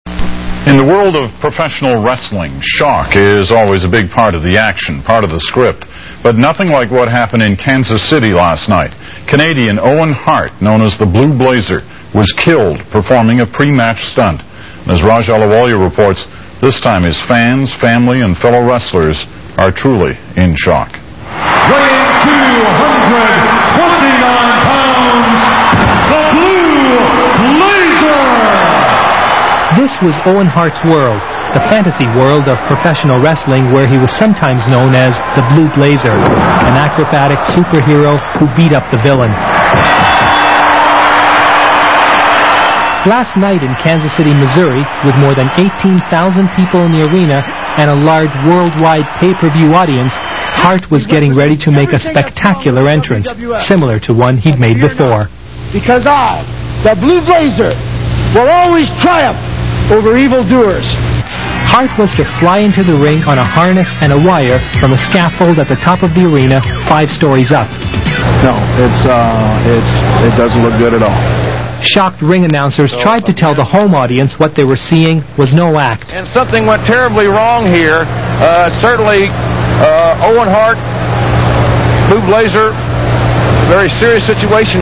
A shocked ring announcer tried to tell the home audience what they were seeing was no act.